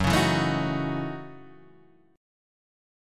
Gbm13 chord